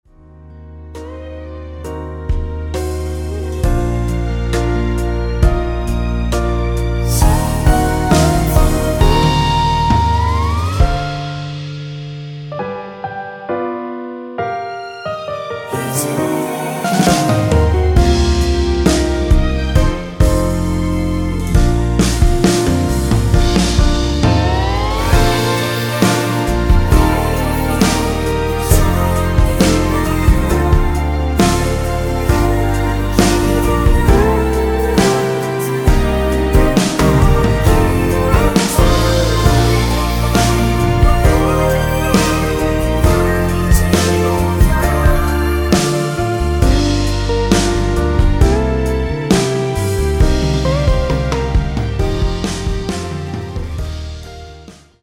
원키에서 (+3)올린 코러스 포함된 MR입니다.(미리듣기 확인)
Bb
앞부분30초, 뒷부분30초씩 편집해서 올려 드리고 있습니다.
중간에 음이 끈어지고 다시 나오는 이유는